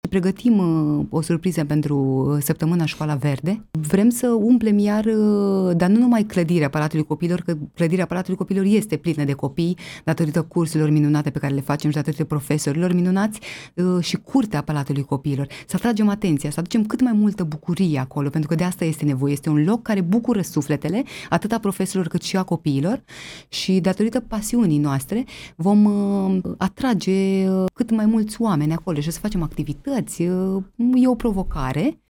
Invitată în emisiunea „Față în față”, la Viva FM, aceasta a vorbit despre planurile pe care le are pentru perioada următoare și despre legătura specială pe care o are cu acest loc.